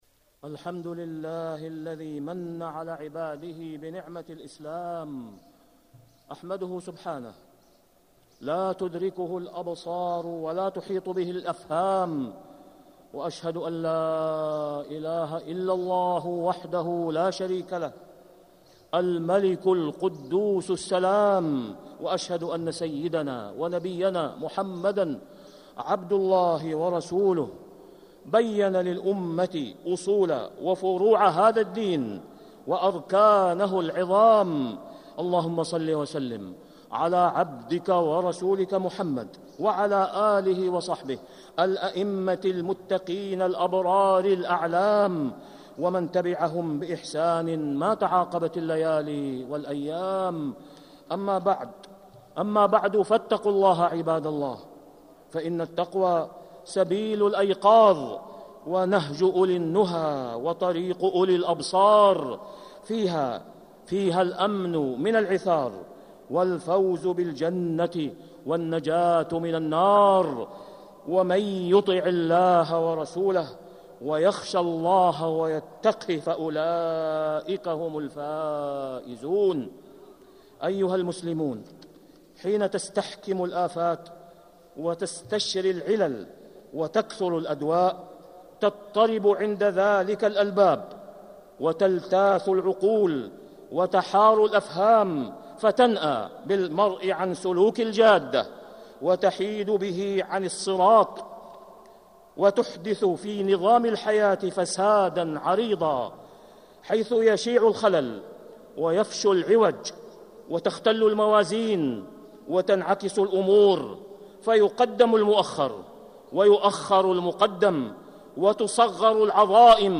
مكة: فقه أولويات العبودية - أسامة بن عبد الله خياط (صوت - جودة عالية. التصنيف: خطب الجمعة